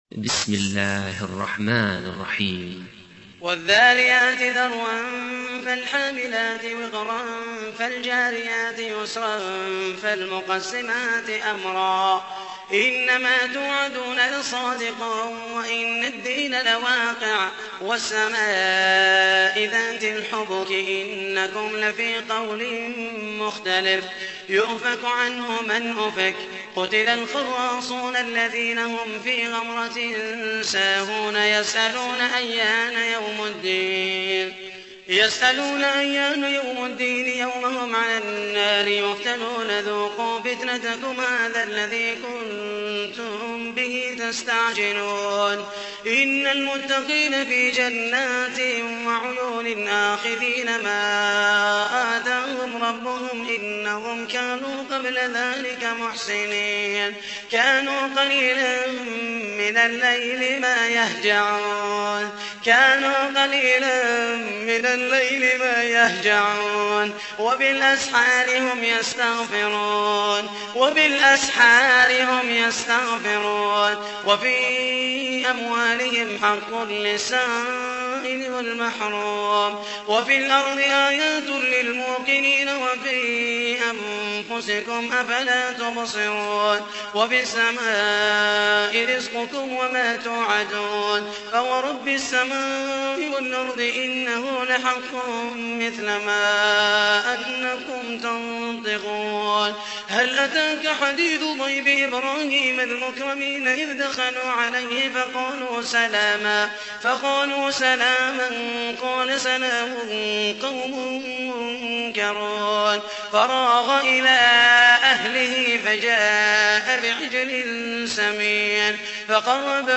تحميل : 51. سورة الذاريات / القارئ محمد المحيسني / القرآن الكريم / موقع يا حسين